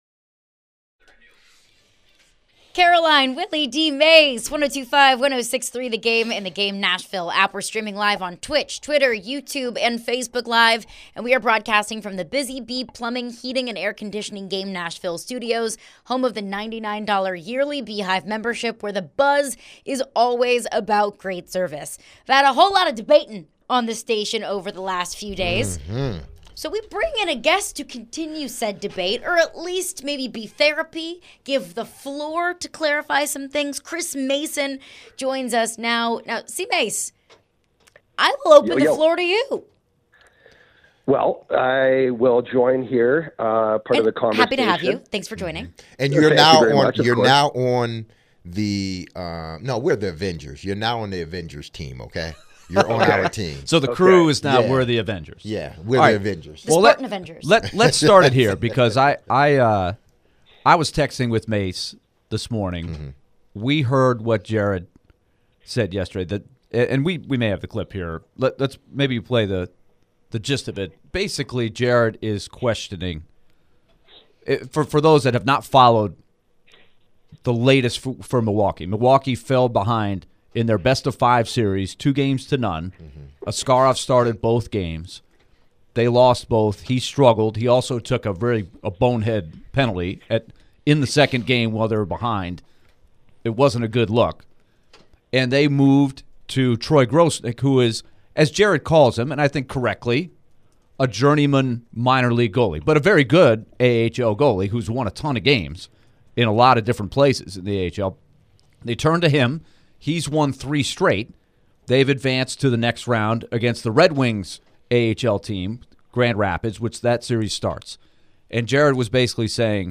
chatted with Nashville Predators TV analyst Chris Mason. Chris shared his thoughts on Yaroslav Askarov and the decision for the Milwaukee Admirals to bench him.